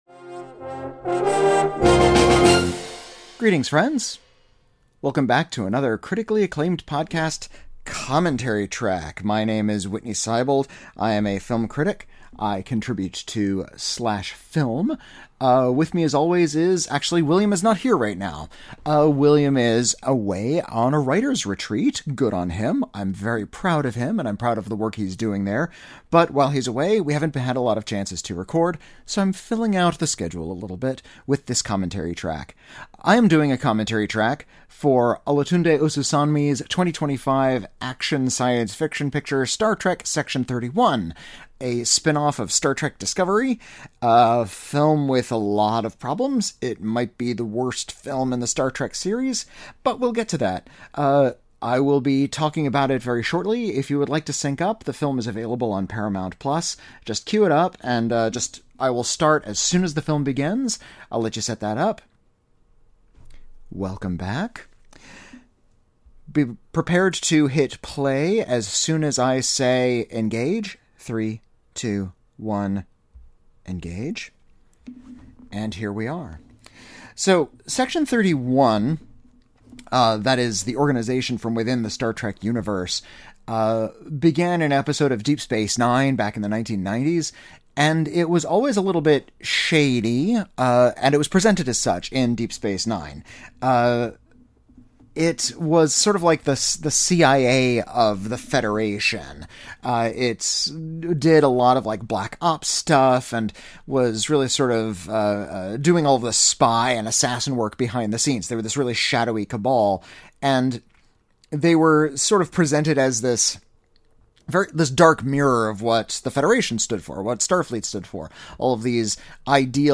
feature-length commentary track